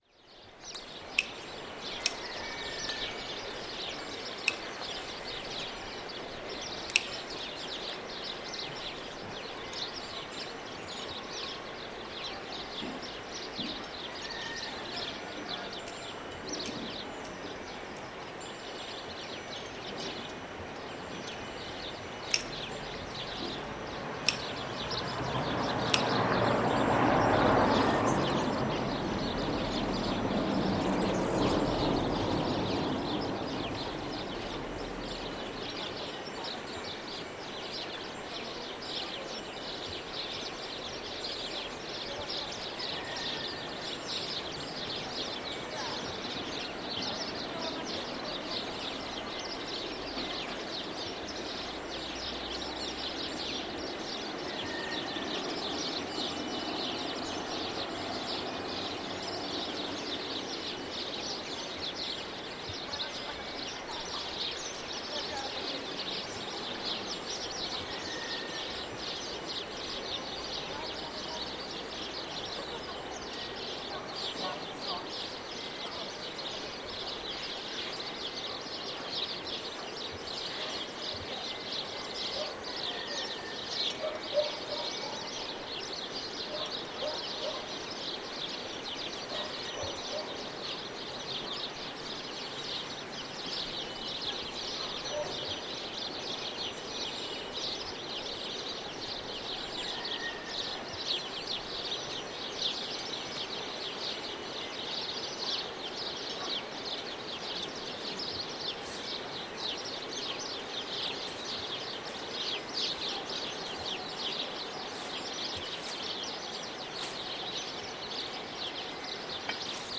Gravação de mulheres a podar a vinha. Gravado com Fostex FR-2L3 e um par de microfones shotgun Rode NTG-2
Tipo de Prática: Paisagem Sonora Rural
Quintãs-Poda-da-Vinha.mp3